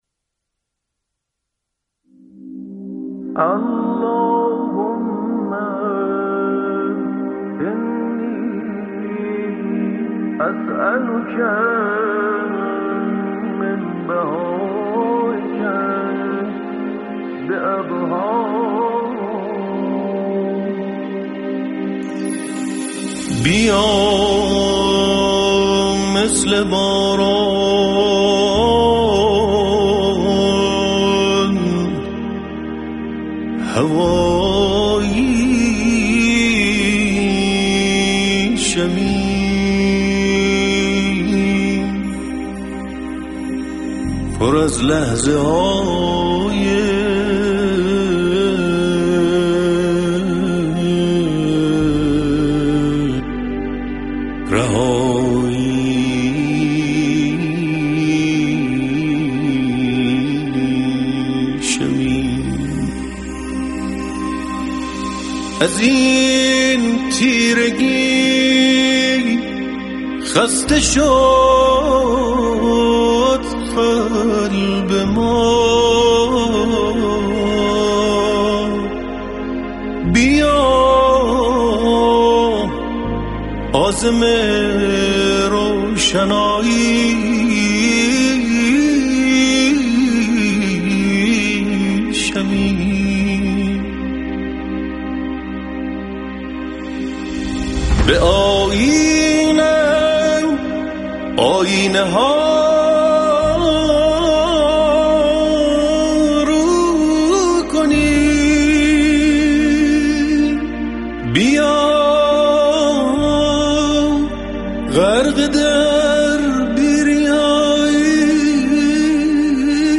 كه یك قطعه، مركب از موسیقی و آواز و نغمه های رمضانی است.